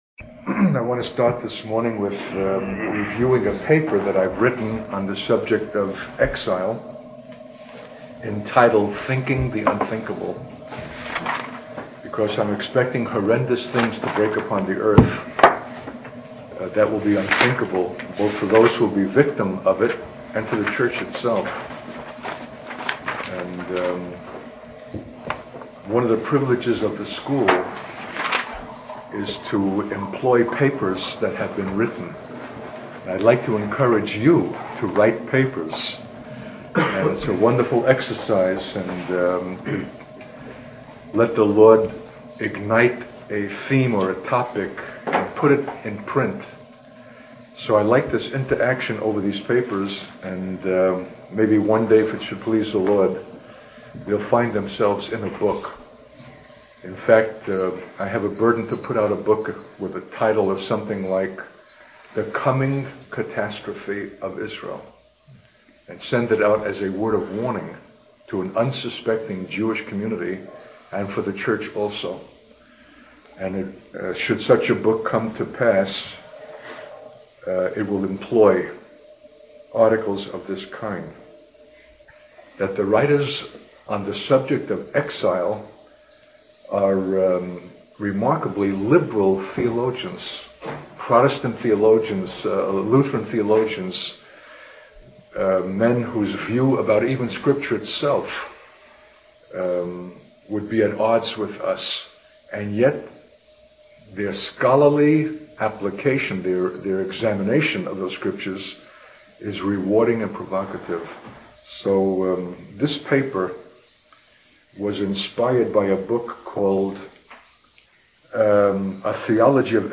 In this sermon, the speaker discusses the topic of exile and the impending catastrophe that he believes will come upon the earth. He emphasizes the importance of sounding a warning to both the church and the Jewish community about this future disaster.